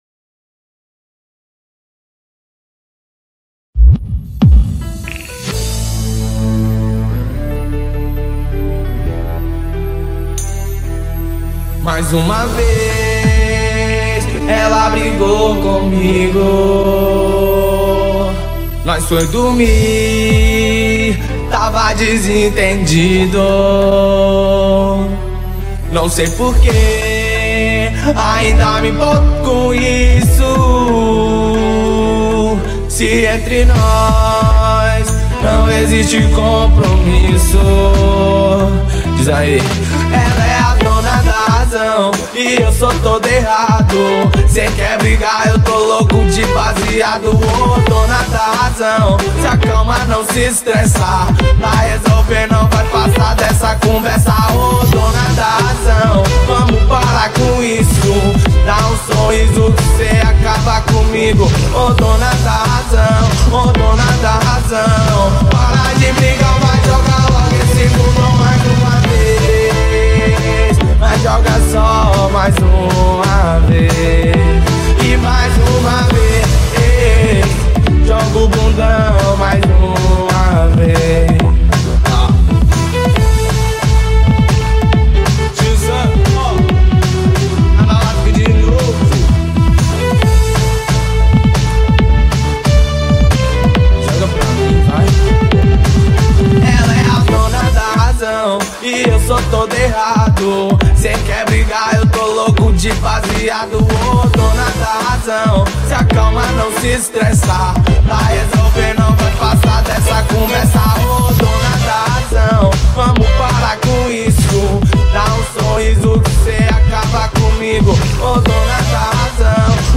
EstiloFunk